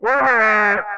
YELL.wav